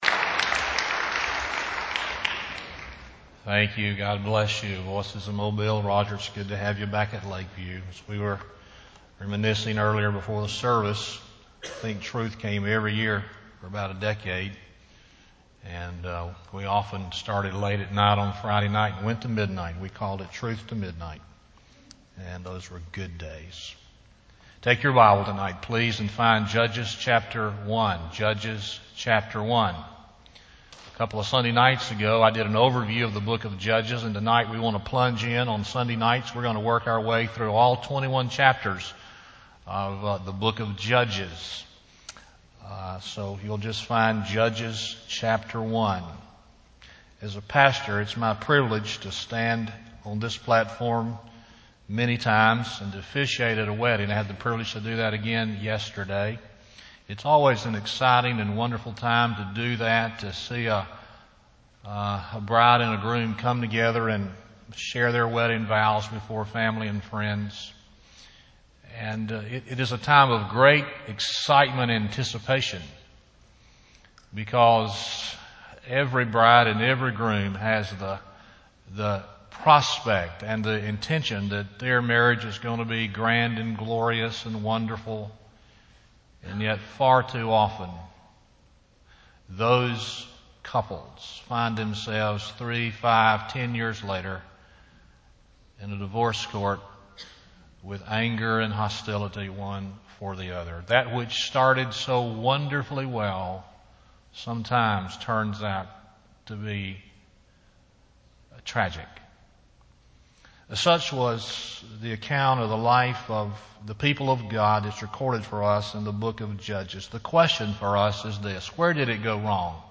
Lakeview Baptist Church - Auburn, Alabama
Sermon